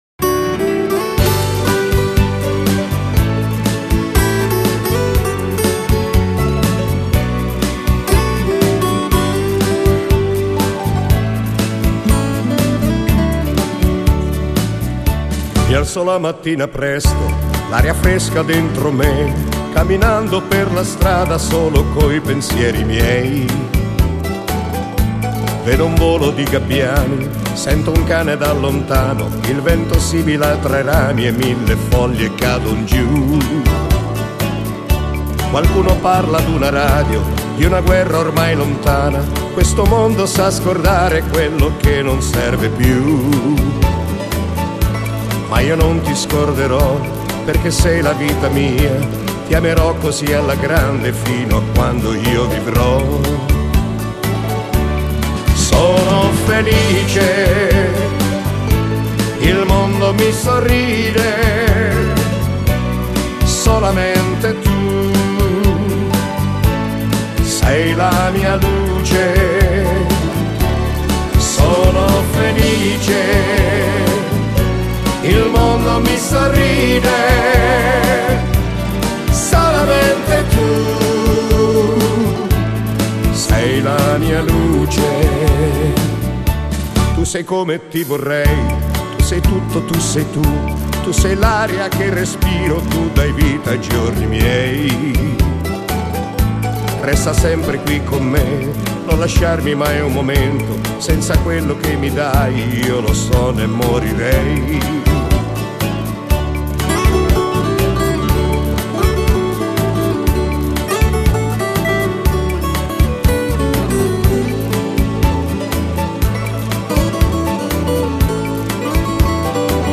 Genere: Beguine